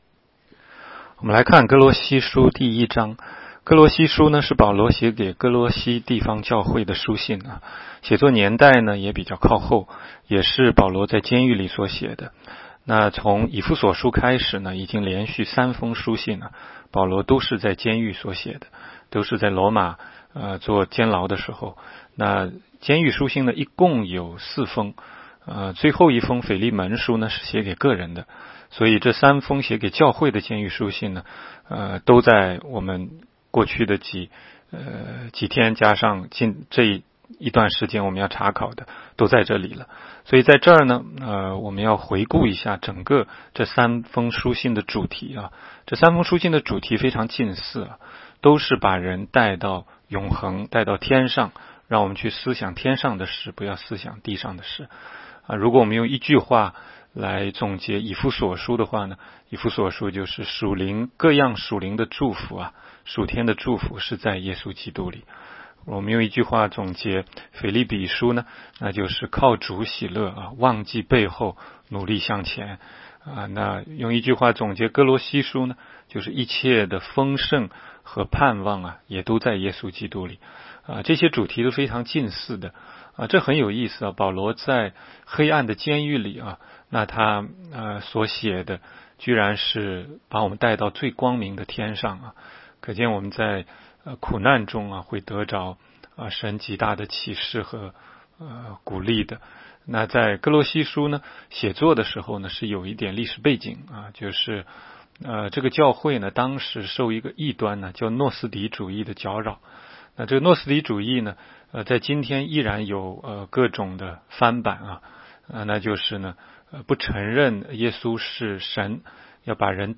16街讲道录音 - 每日读经-《歌罗西书》1章
每日读经